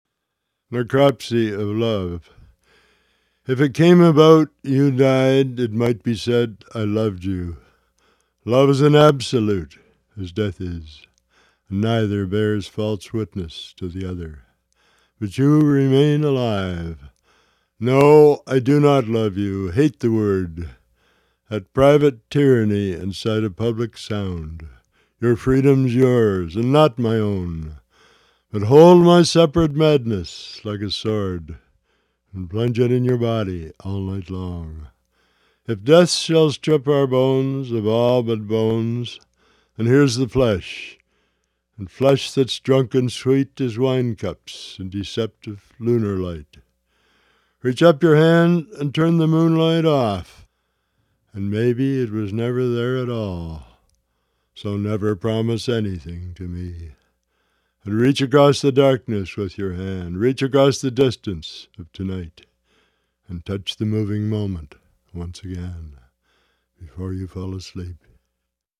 The late great Canadian lyric poet Al Purdy’s work lives on—and in his own voice—in Necropsy of Love, an audio poetry collection interspersed with fascinating interviews.